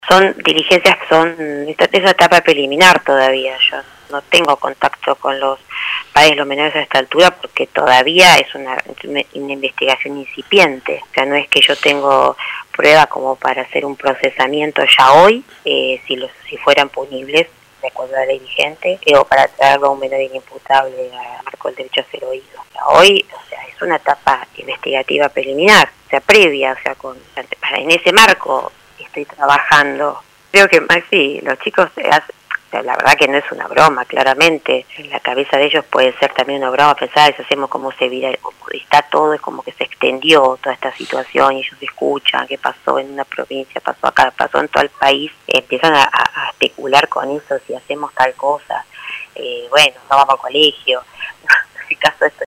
Luego de los operativos efectuados en Tres Arroyos que derivaron en secuestros de varios celulares en distintos domicilios, por las amenazas virtuales a través de redes sociales y pintadas en las escuelas, LU 24 entrevistó a la titular de la UFIJ 4 del Fuero de Responsabilidad Penal Juvenil, Dra. Marina Vizzolini, quien hizo el análisis de la situación